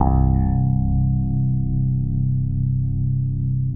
14BASS01  -L.wav